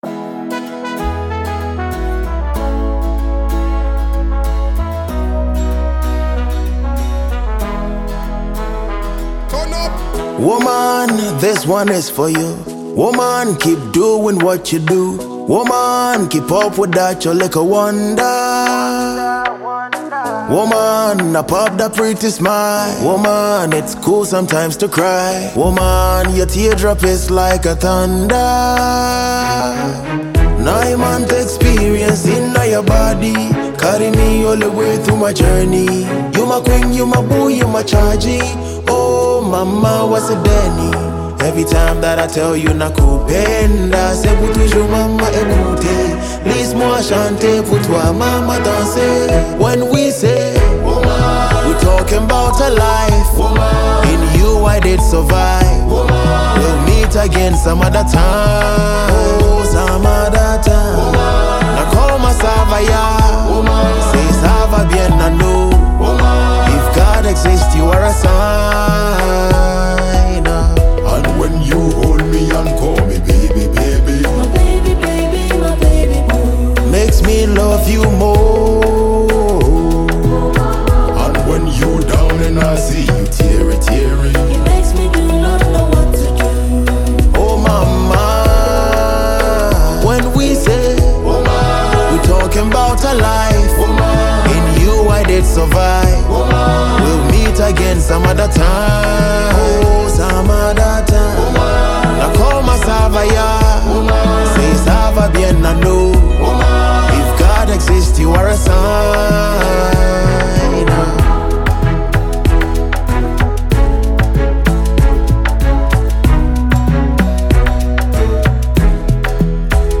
soul-touching track
With his poetic delivery and rich vocals
Afrobeat rhythms